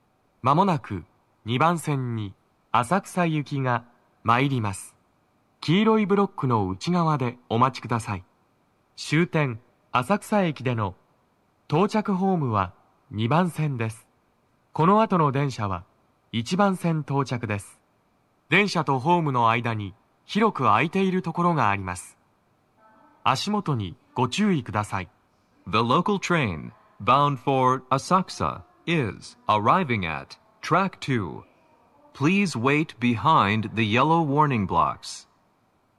鳴動は、やや遅めです。
2番線 浅草方面 接近放送 【男声
接近放送3